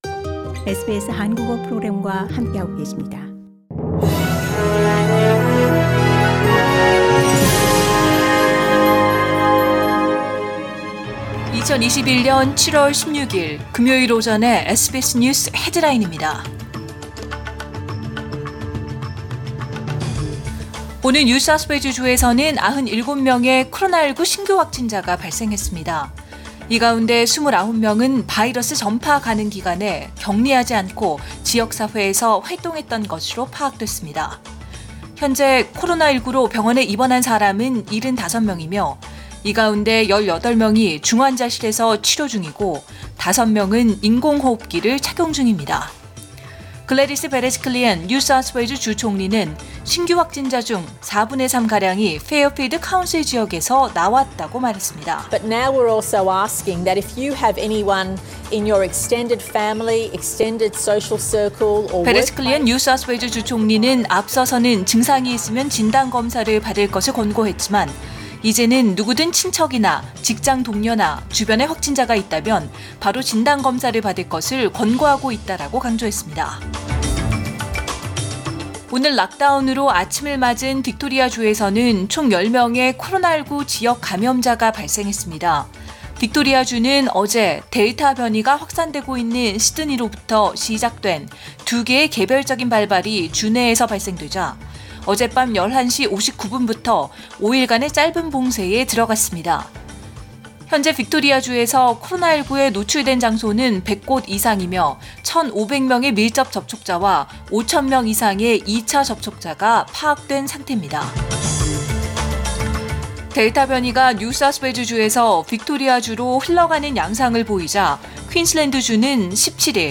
2021년 7월 16일 금요일 오전의 SBS 뉴스 헤드라인입니다.